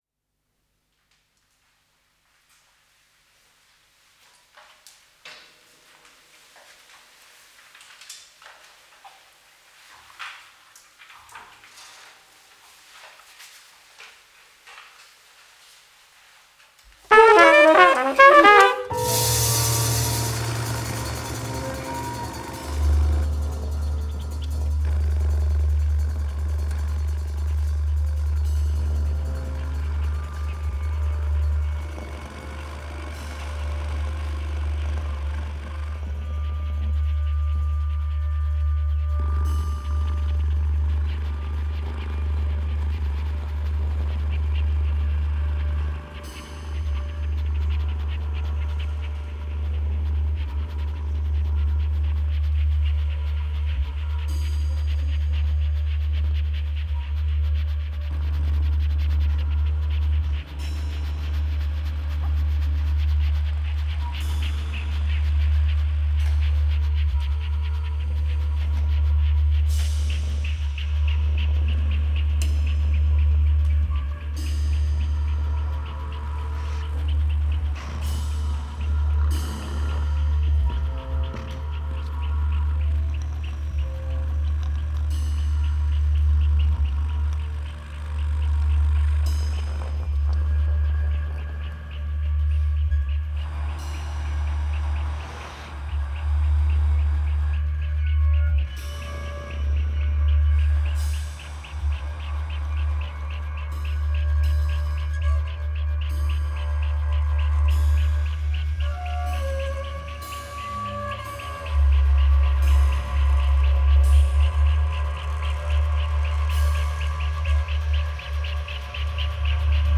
piano
tenor saxophone
alto saxophone
drums
guitar
double bass
trumpet